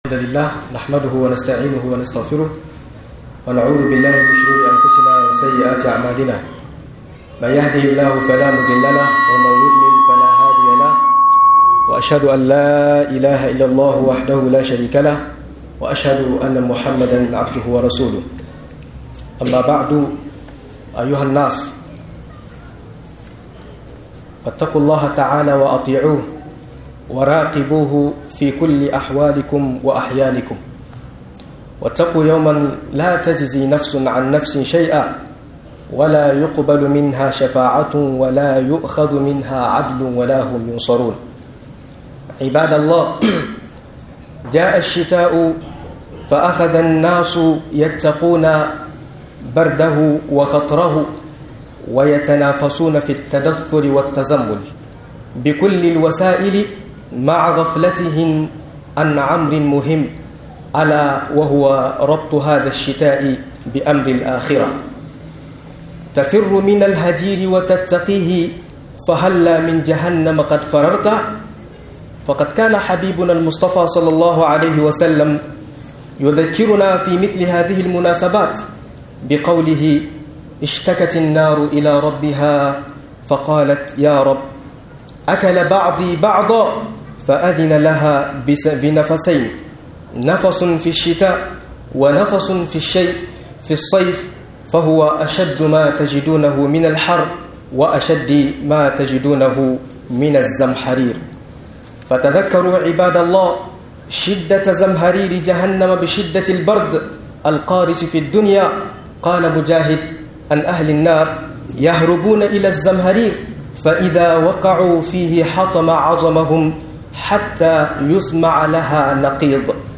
A cikin Sanyi akoy abubuwan lura - MUHADARA